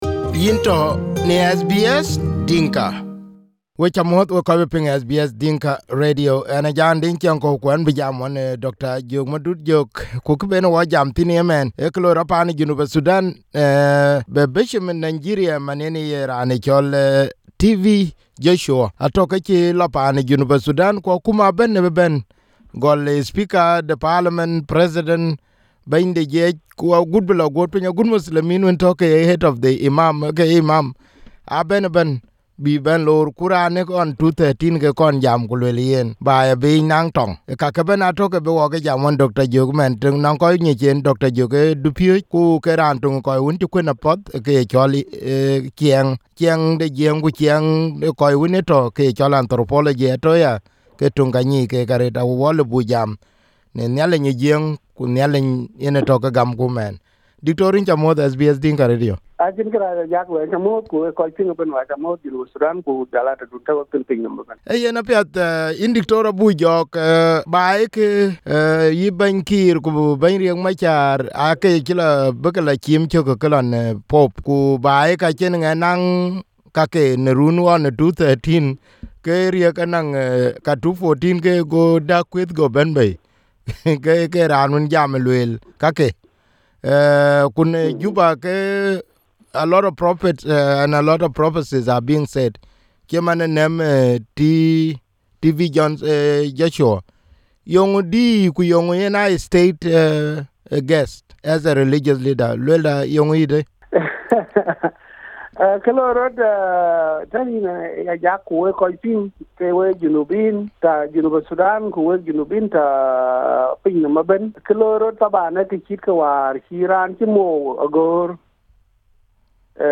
(Audio in Dinka) Dr Jok Madut Jok is a lecturer and former undersecretary in the Government of South Sudan and currently a lecturer in New York. In this interview, Dr Jok talks about the visit of TB Joshua to South Sudan and how the political wanted to classify South Sudan’s conflict as ‘spiritual’.